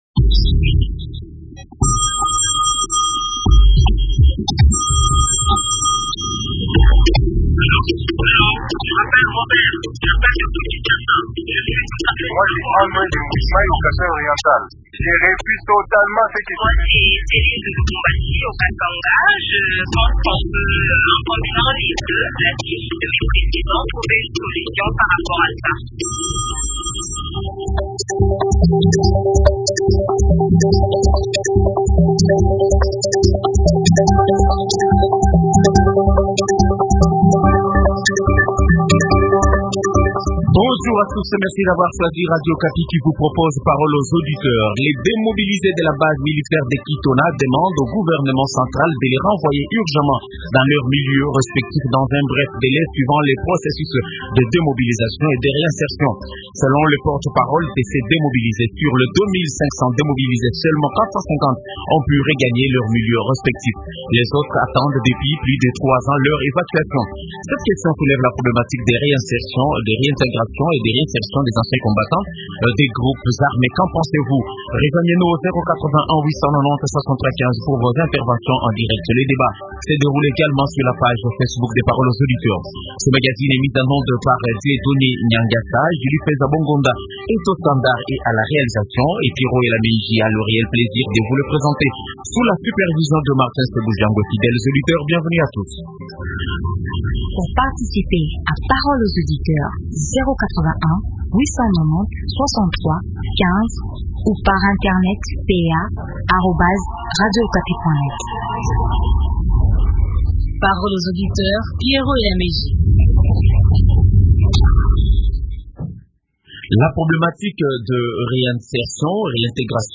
Invité: Juvenal Munubo, Député National membre de la commission Défense et sécurité et rapporteur de la sous-commission des forces armées à l’Assemblée nationale.